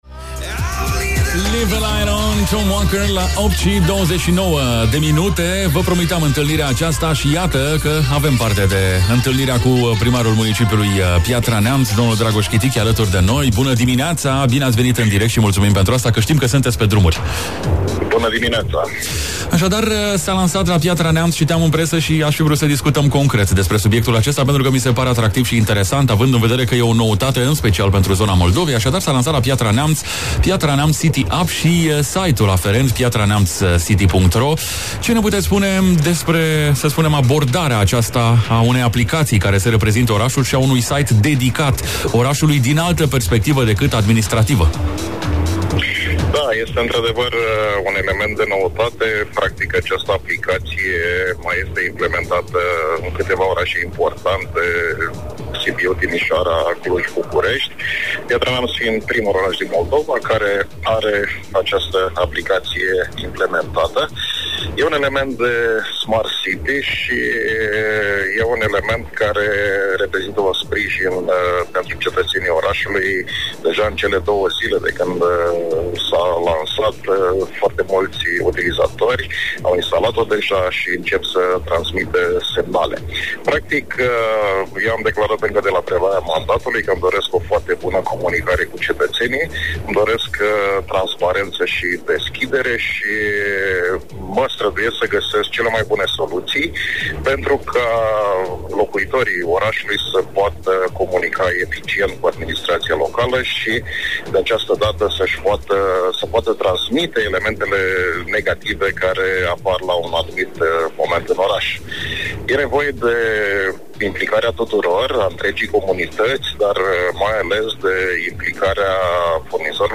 Amănunte ne-au fost oferite de primarul de Piatra Neamț, Dragoș Chitic, la “Dimineți Animate”, pe 94.7 Mhz, la Viva FM!
Piatra-Neamt-City-App-Primar-Dragos-Chitic.mp3